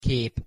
Pronunciation Hu Kép (audio/mpeg)